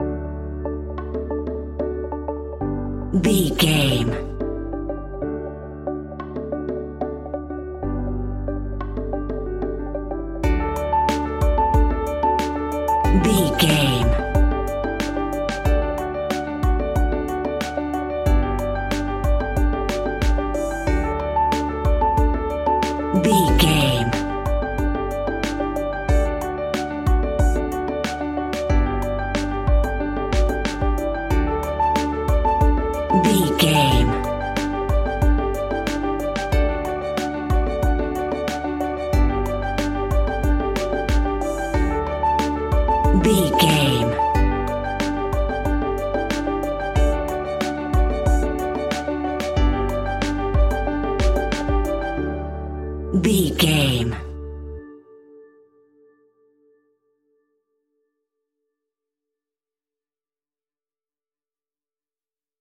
Ionian/Major
D
energetic
indie pop rock music
upbeat
groovy
guitars
bass
drums
piano
organ